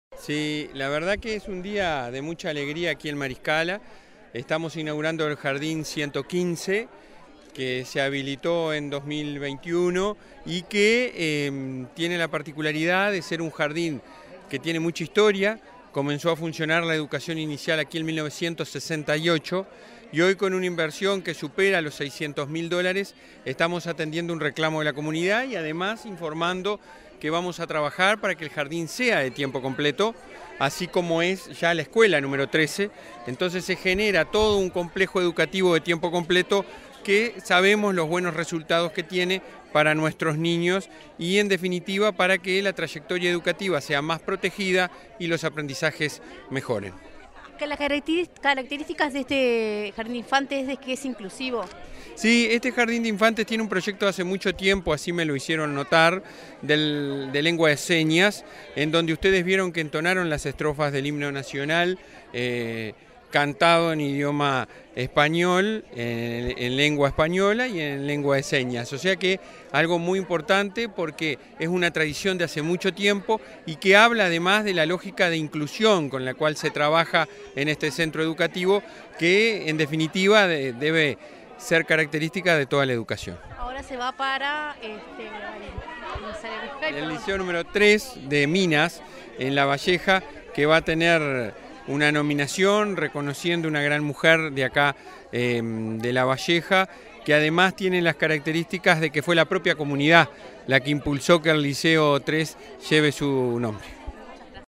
Entrevista al presidente de la ANEP, Robert Silva
Tras varias inauguraciones en el departamento de Lavalleja, este 4 de agosto, Comunicación Presidencial dialogó con el presidente del Consejo